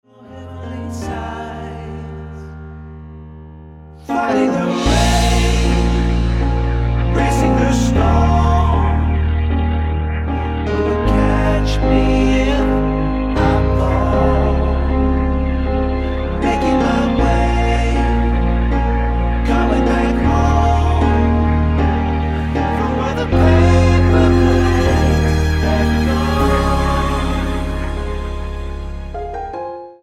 Tonart:Ab-Bb mit Chor